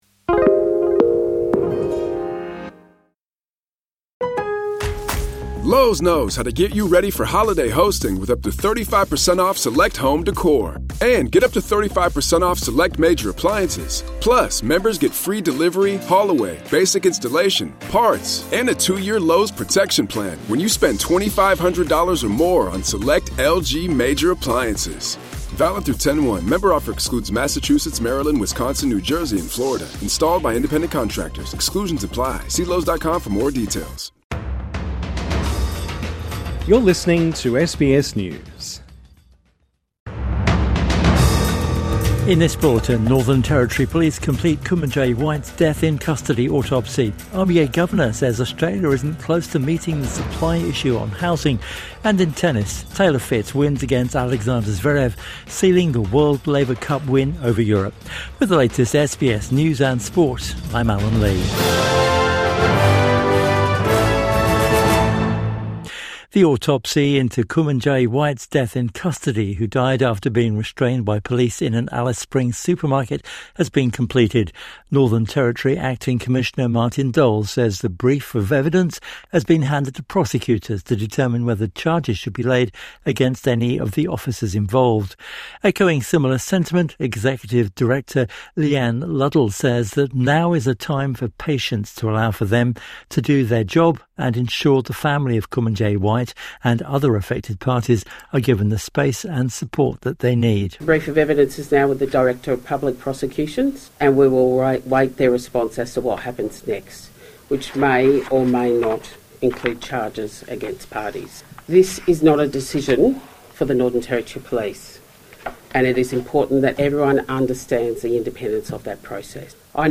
Evening News Bulletin 22 September 2025